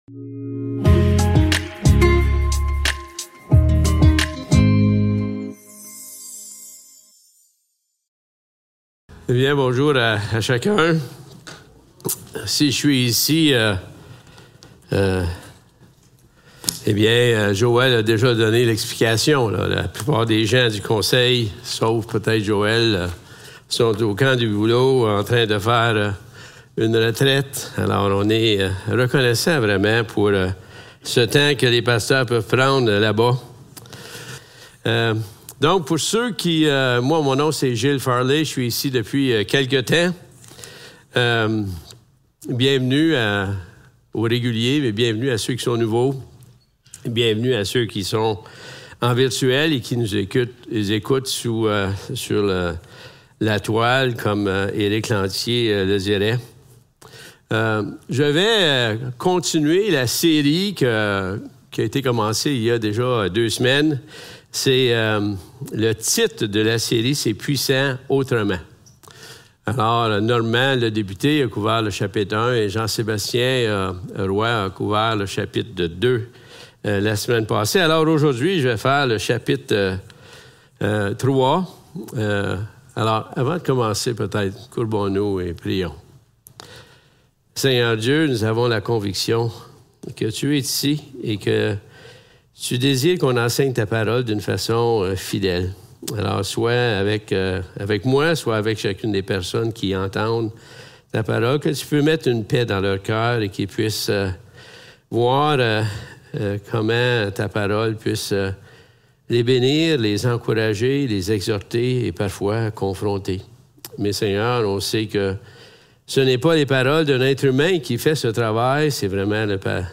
2 Corinthiens 3 Service Type: Célébration dimanche matin Description